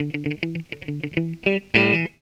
GTR 40 EM.wav